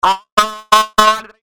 Chaotic Vocal
The chaotic sounding vocal that repeats throughout the track was obtained by using the Envelope Follower function within Adobe Audition.
Vocal Chaos.mp3